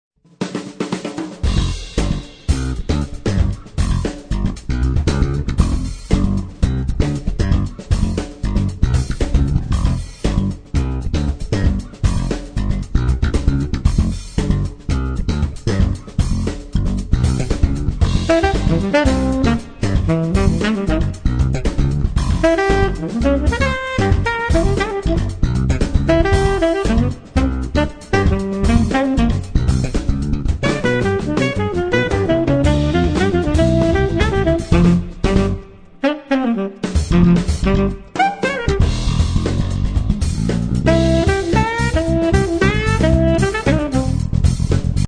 noty pro saxofon
Melodie-Instrumente in Es (Alt-Saxophon/Alt-Klarinette)